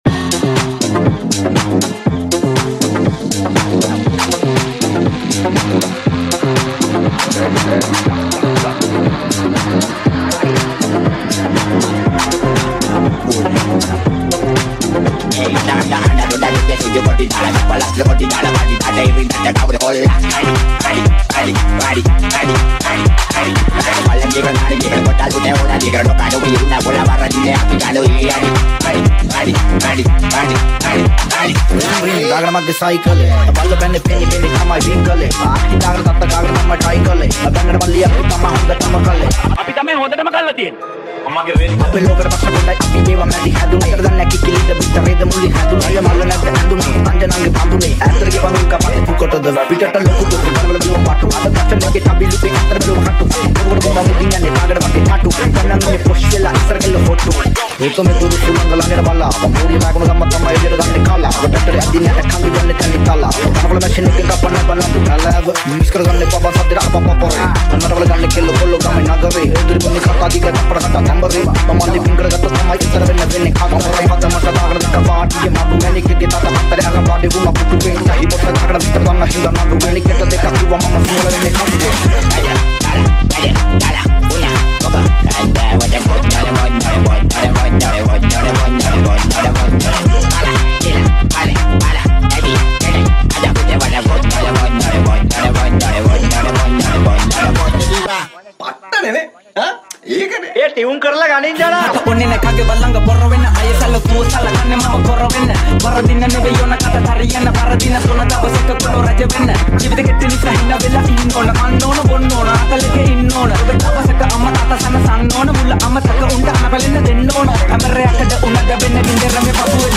Genre - TECH HOUSE
BPM - 120